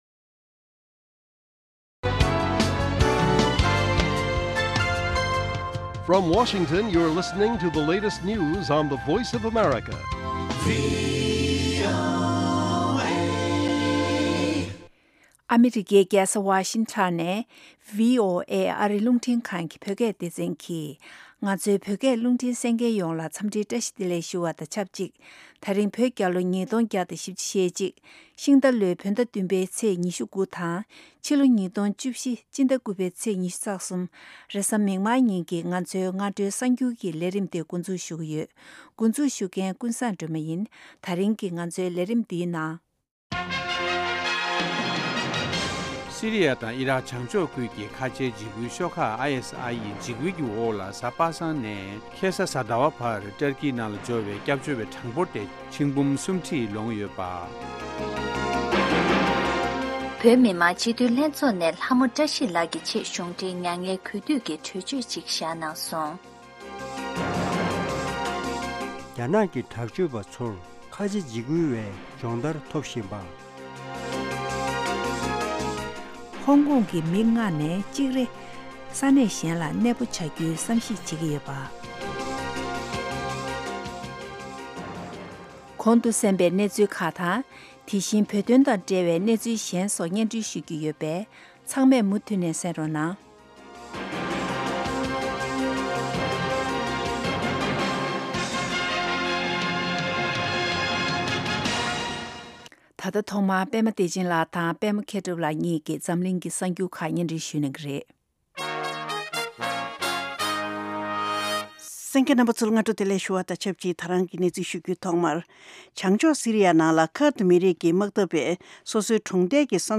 སྔ་དྲོའི་གསར་འགྱུར།